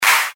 Special CRACK function creates some analog clap-like sounds: basicall it simply applies a kind of configurable saw-shapped Envelope
demo HEAR crack
crack.mp3